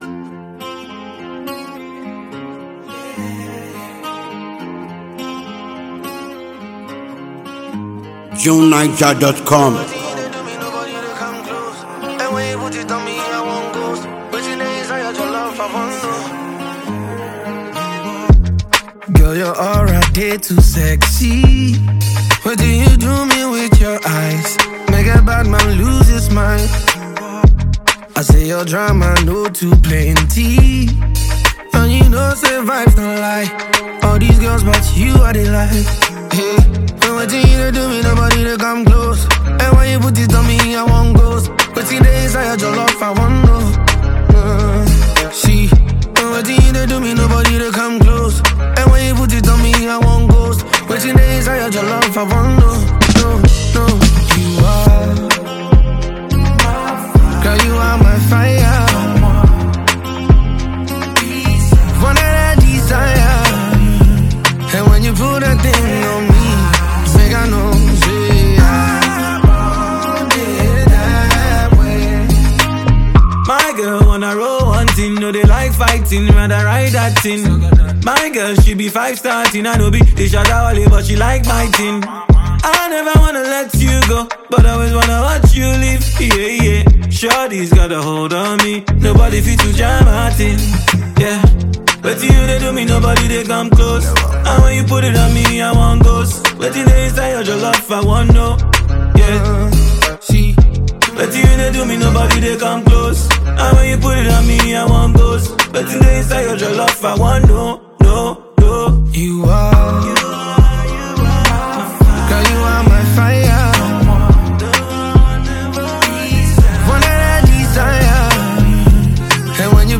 smouldering and captivating song